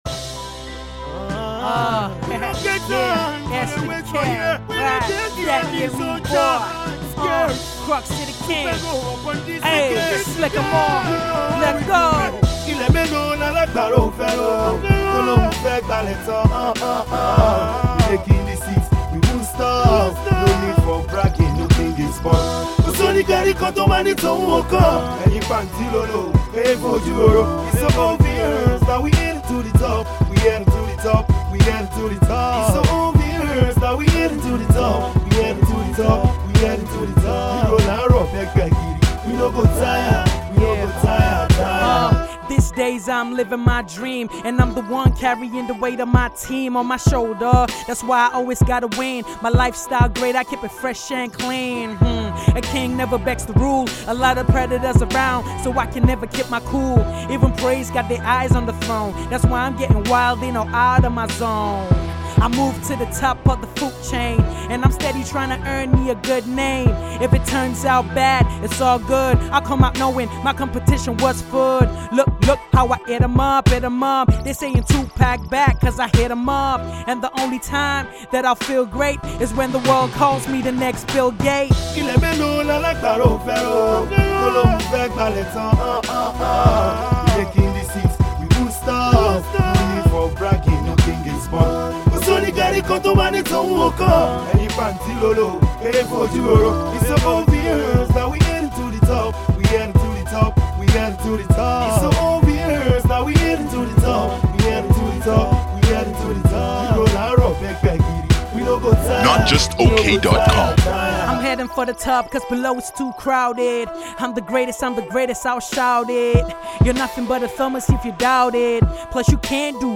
Korrect street hiphop we’ve got here.